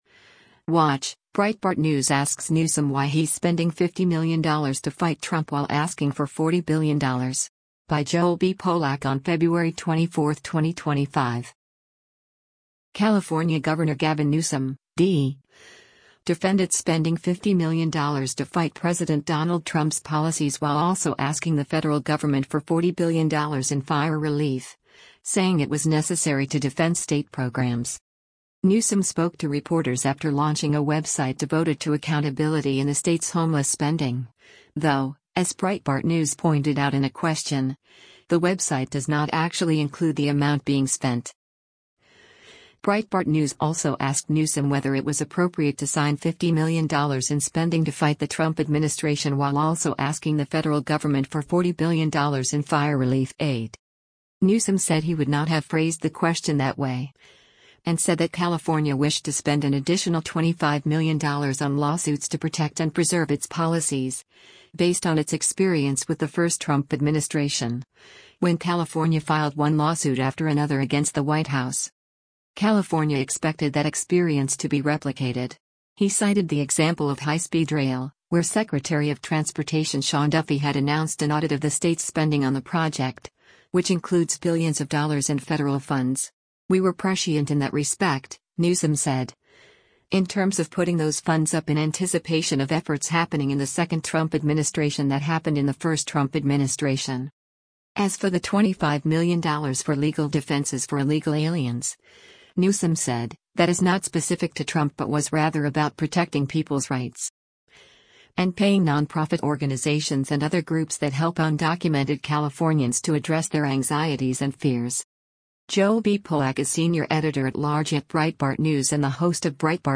Newsom spoke to reporters after launching a website devoted to “Accountability” in the state’s homeless spending — though, as Breitbart News pointed out in a question, the website does not actually include the amount being spent.